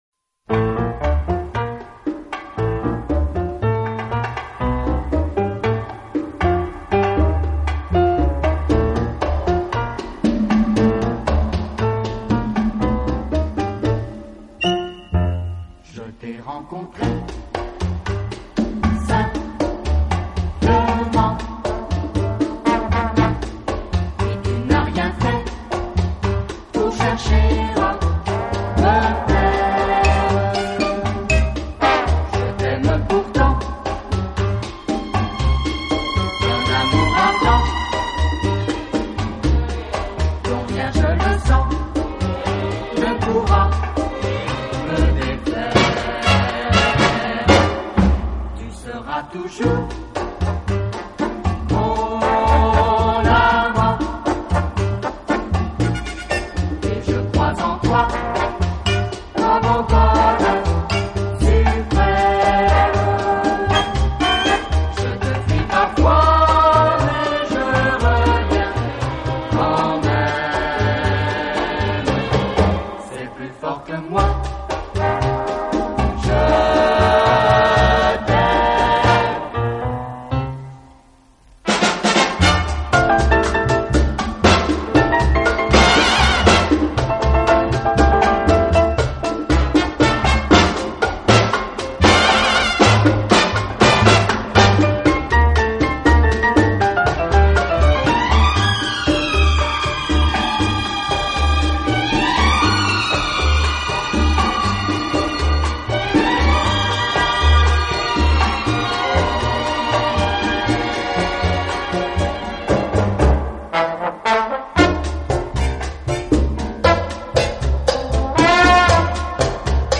【轻音乐】
每当你听到那种独特、新鲜、浪漫的弦乐音响，明晰、活泼的节奏和铜管、打击乐器的